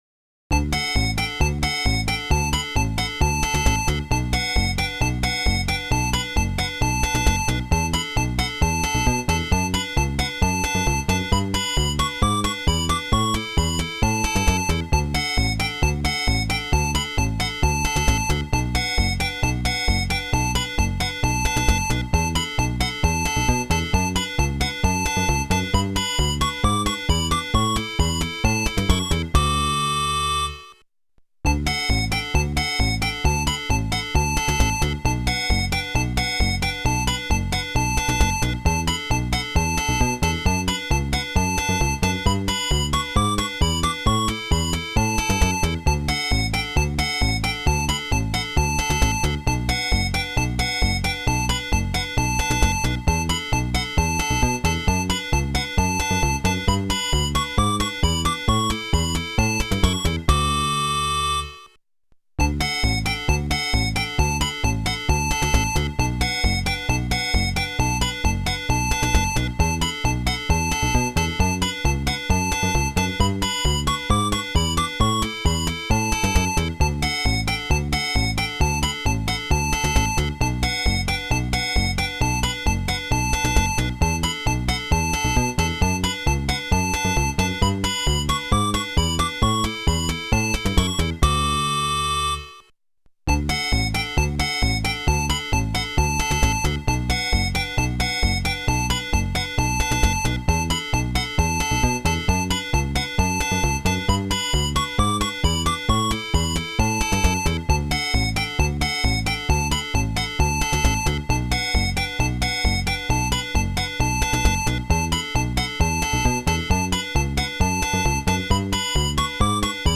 MuSICA系ドライバ
YM2413 OPLL